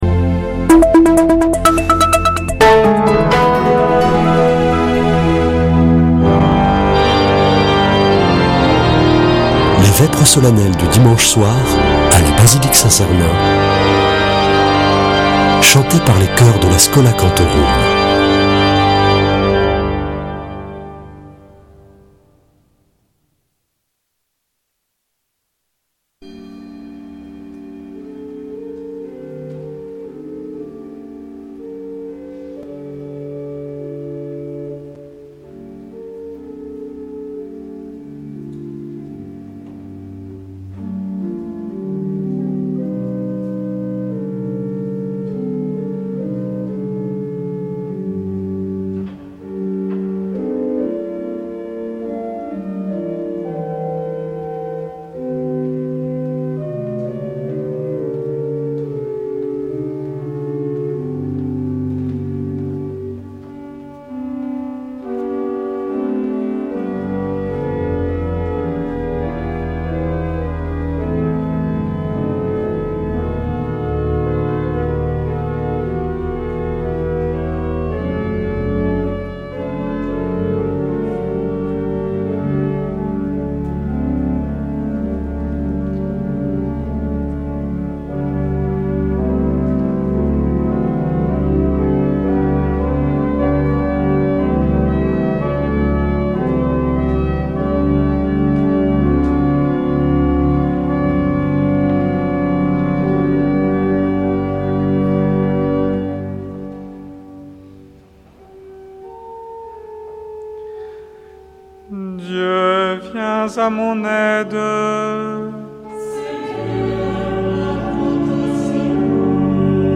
Vêpres de Saint Sernin du 19 janv.
Une émission présentée par Schola Saint Sernin Chanteurs